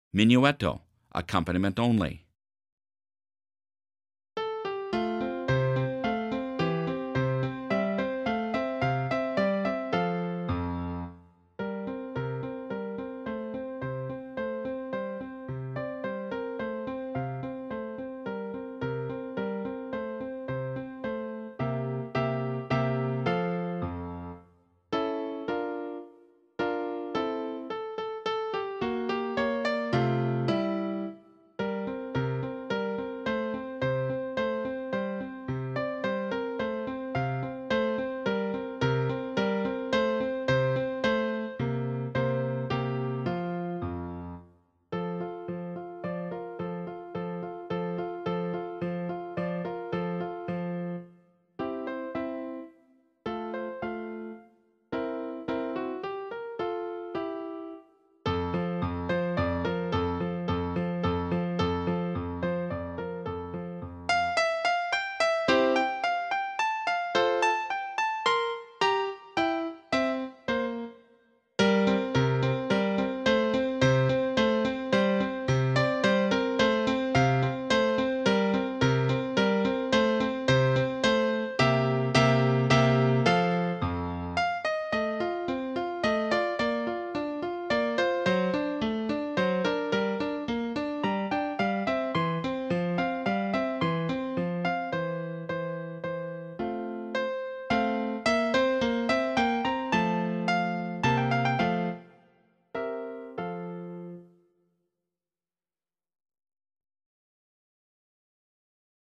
Horn-Minuetto-Accomp.mp3